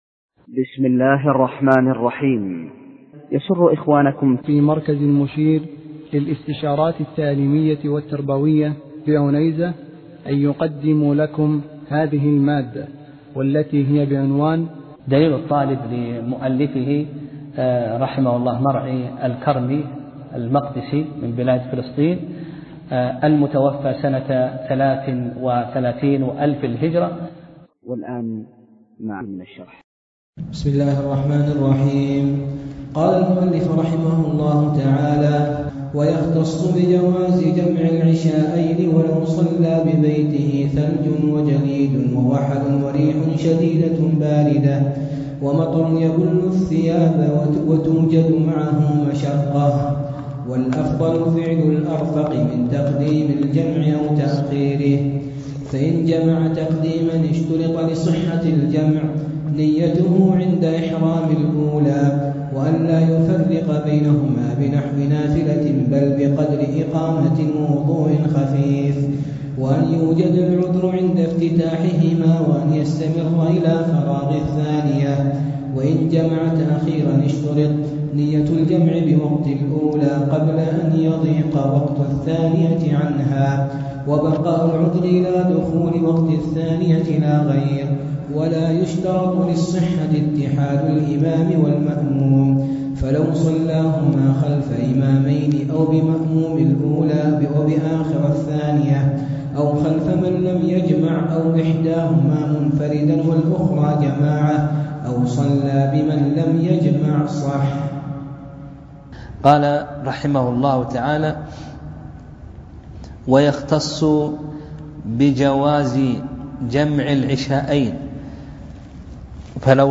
درس (17) : فصل في الجَمع (2)